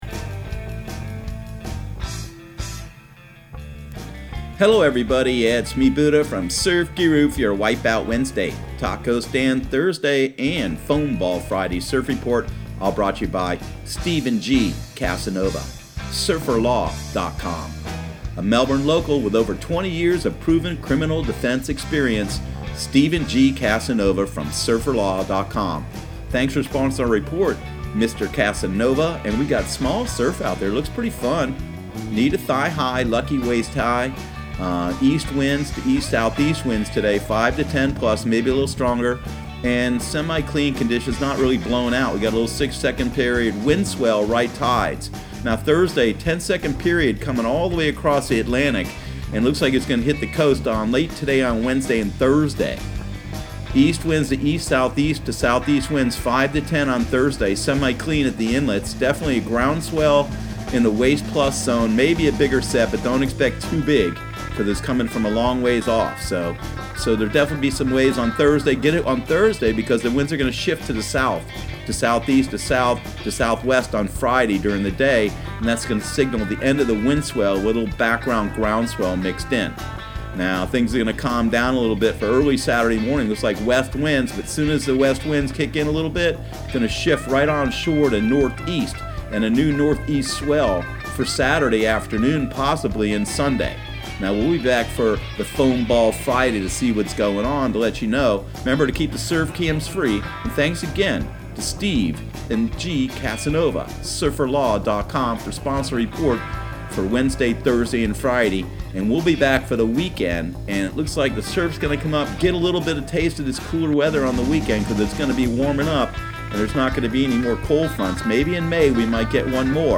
Surf Guru Surf Report and Forecast 04/24/2019 Audio surf report and surf forecast on April 24 for Central Florida and the Southeast.